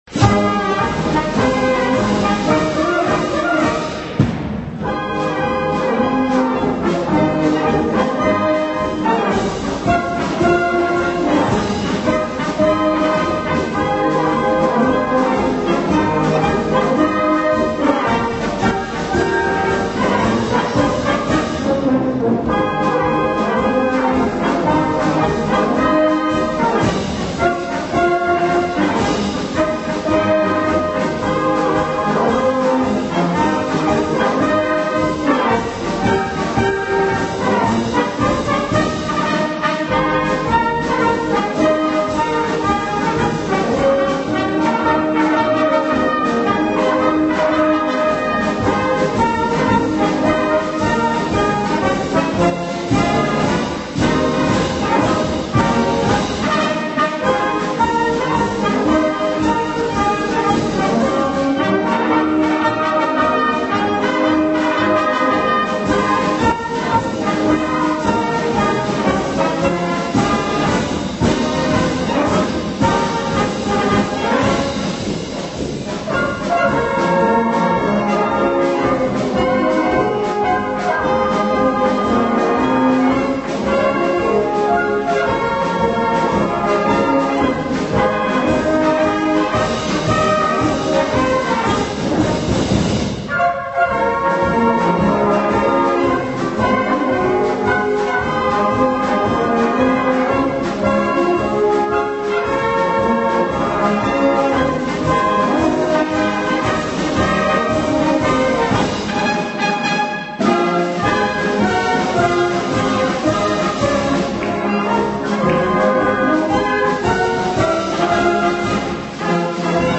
Blasmusik:
Märsche: